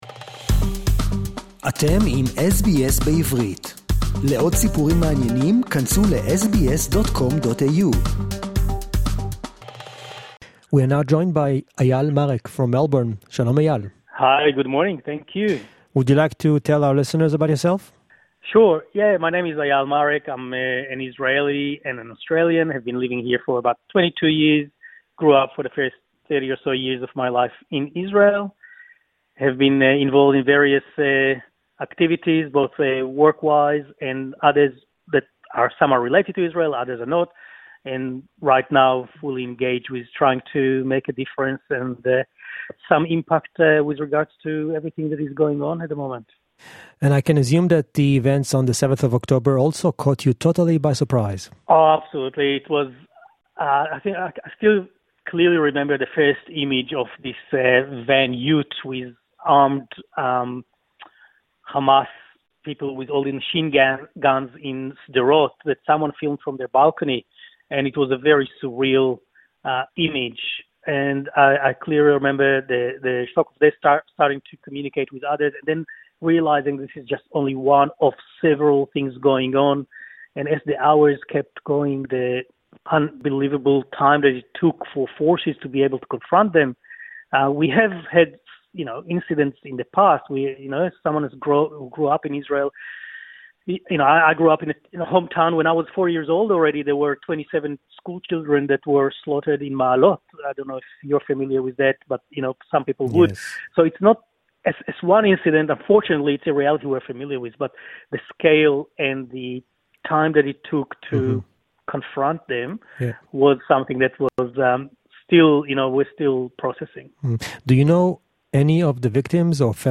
(English Interview) A special event held in Caulfield park gave attendees the opportunity to send digital postcards with a personalised message to the hostages in Gaza, with the hope that they will receive it once they are released from captivity.